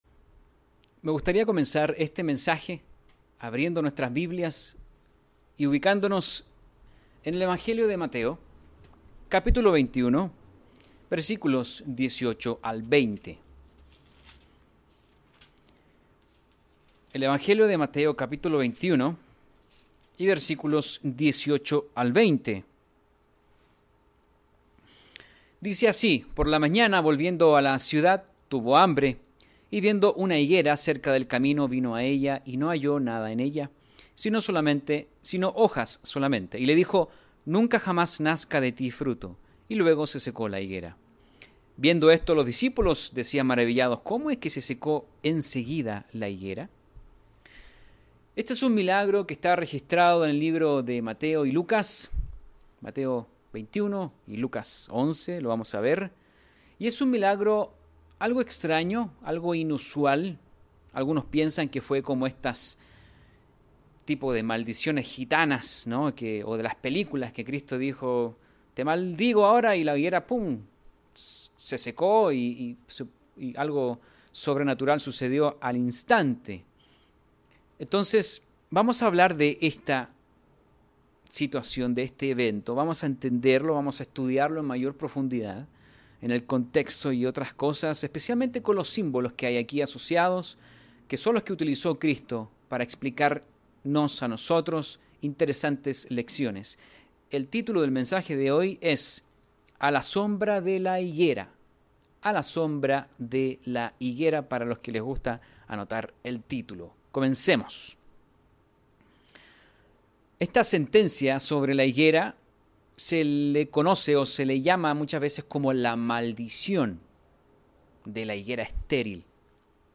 Uno de los símbolos usados por Jesucristo para enseñar a sus discípulos fue la higuera, que asemeja la madurez y fertilidad espiritual que se desea del cristiano. Mensaje entregado el 21 de noviembre de 2020.